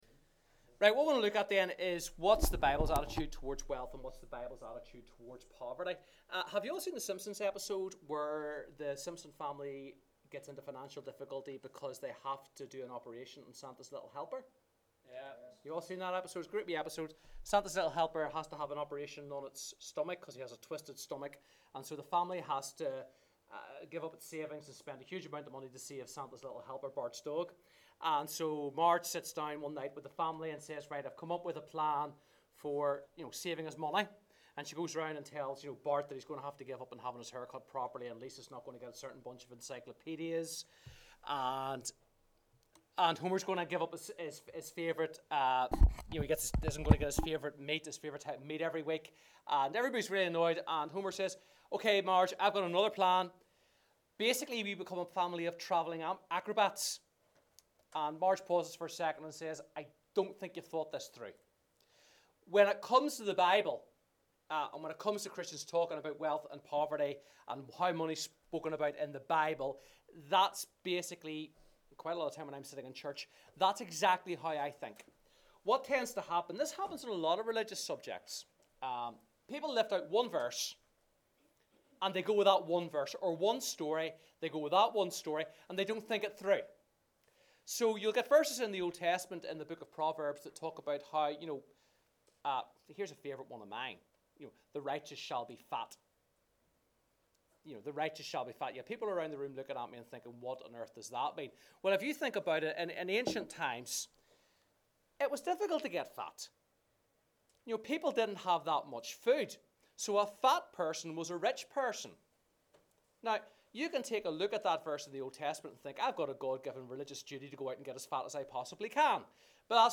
This short talk is aimed at beginners; it is also suitable for Religious Studies Key Stage 4 students (14-16), especially those studying the life of Jesus